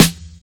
DOUBZ_SNR2.wav